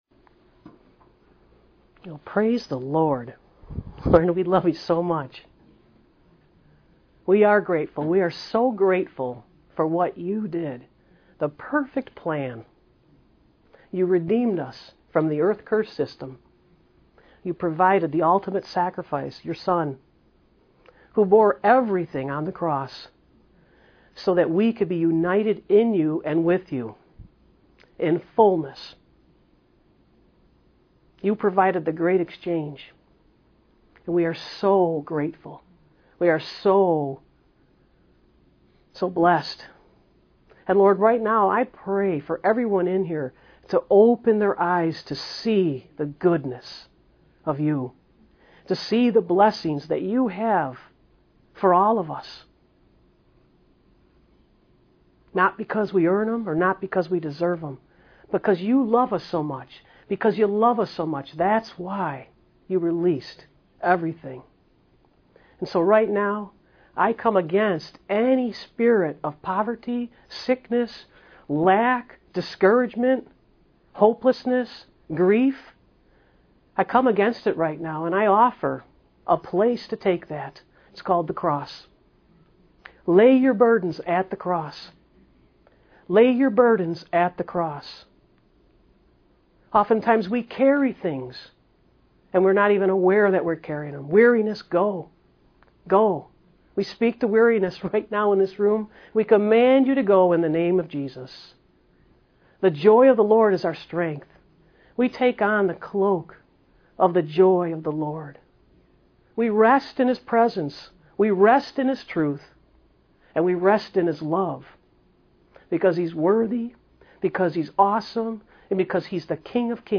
Sermons | Spirit Of God Ministries WorldWide
Spirit Of Intercession - Live